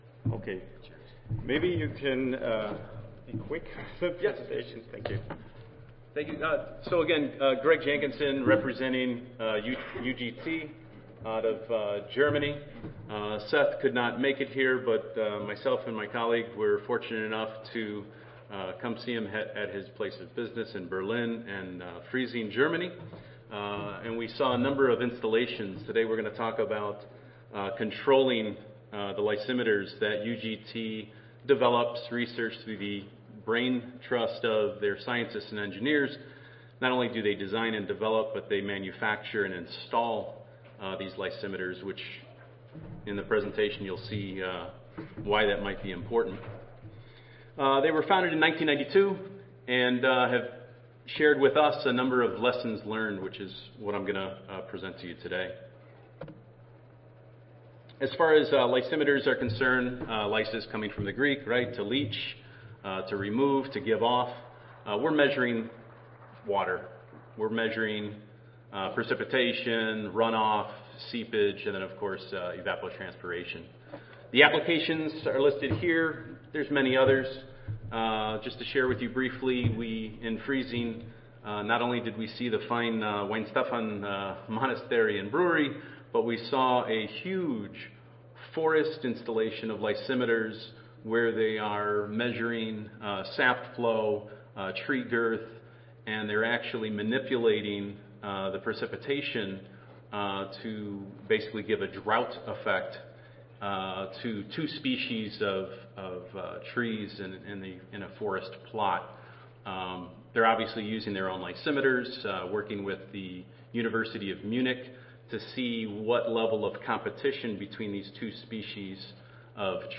Max Planck Institute for Biogeochemistry Audio File Recorded Presentation